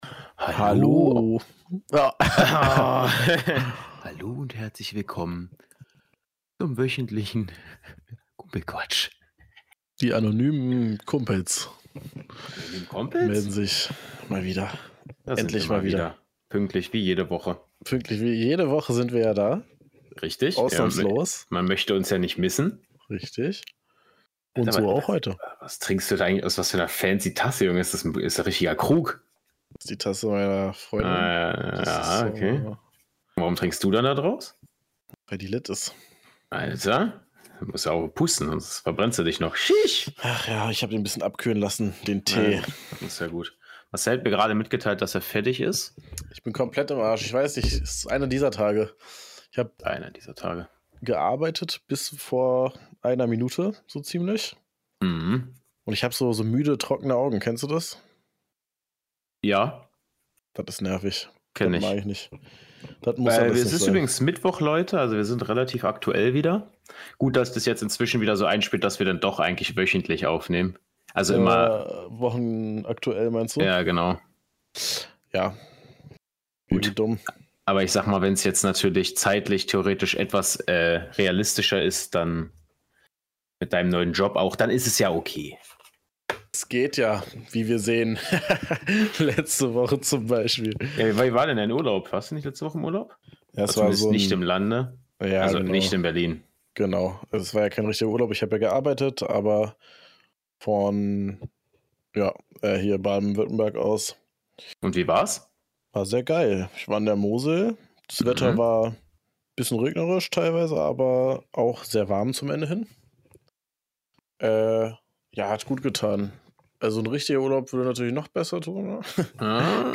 Low-Energy-Folge sowy